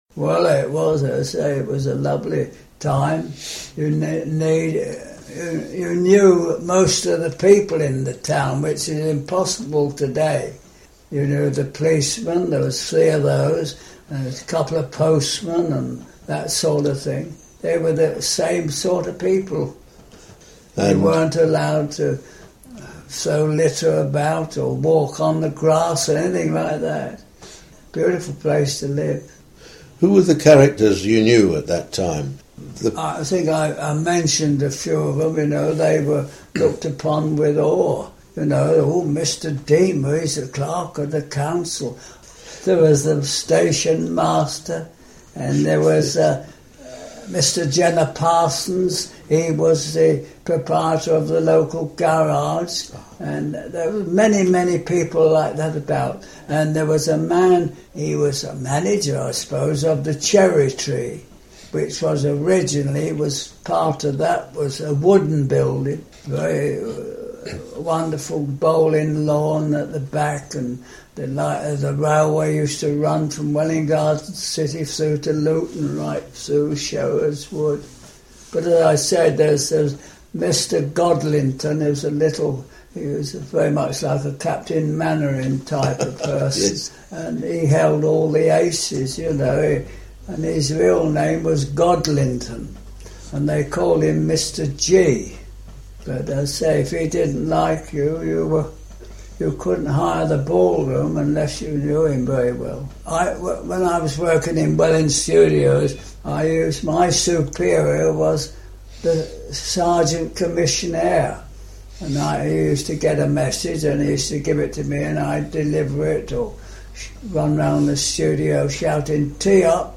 Memories - early residents contributed their recollections of the town which were digitally recorded as oral histories by a team of volunteers.